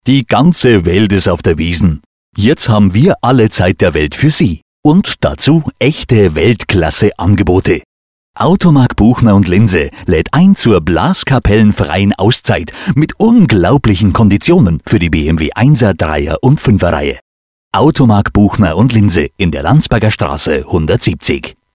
deutscher Sprecher für Werbespots, Hörspiele und Hörbücher, Podcasts, E-Journals und Business Radio Special: Münchener Dialekt Bayerisch
Sprechprobe: eLearning (Muttersprache):
german voice over talent